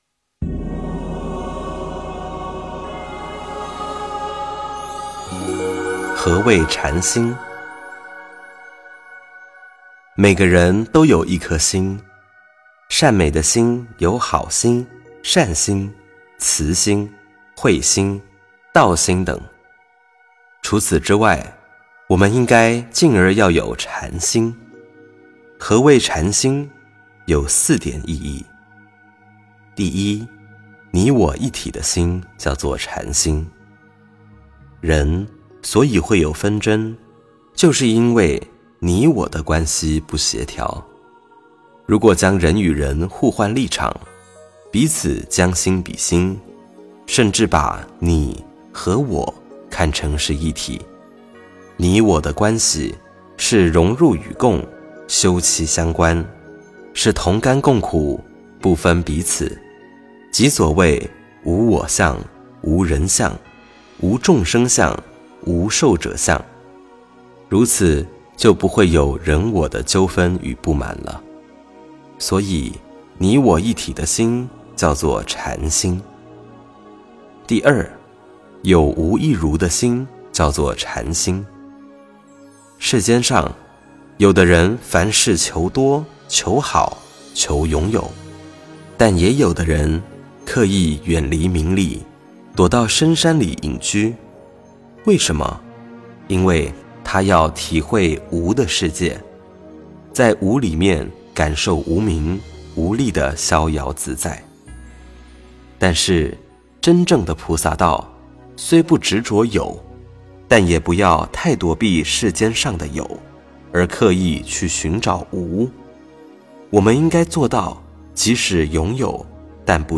--佚名 点我： 标签: 佛音 冥想 佛教音乐 返回列表 上一篇： 29.何谓禅？